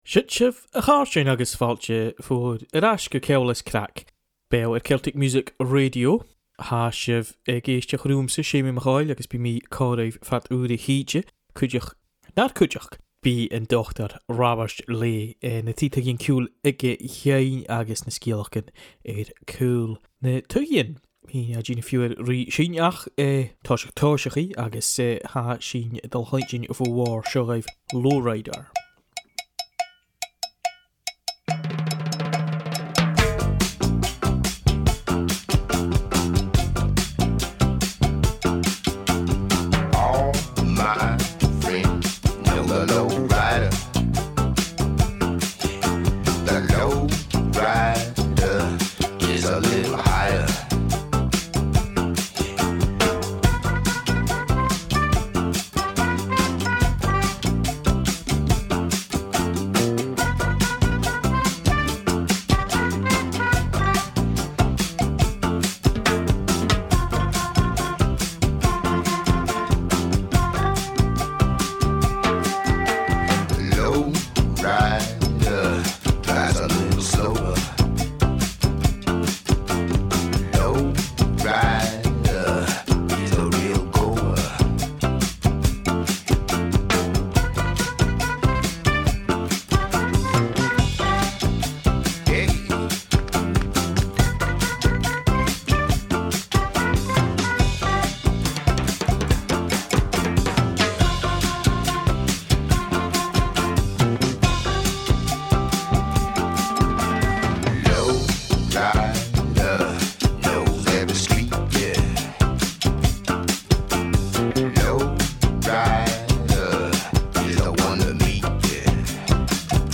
Ceòl is Craic @ Celtic Music Radio is our weekly broadcast featuring the best of new Gaelic music alongside an eclectic mix of jazz, electronica, Americana, world music
Radio